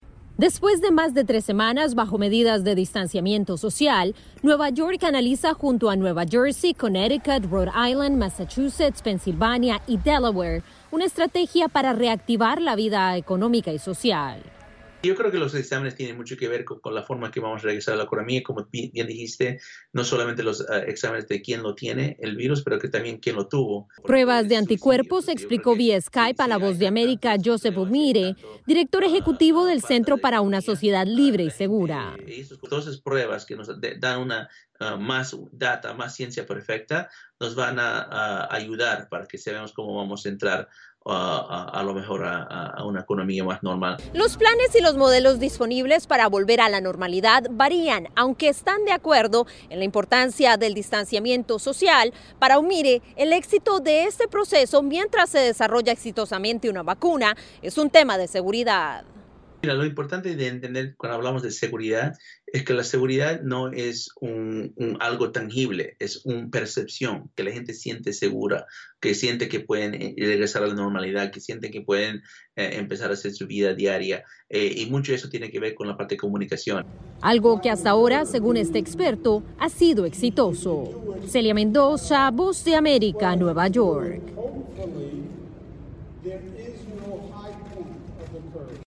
En medio de la pandemia del COVID-19, la Universidad de Harvard publicó un estudio en el que asegura que sin una vacuna el distanciamiento social podría extenderse hasta el 2022. Desde la Voz de América en Nueva York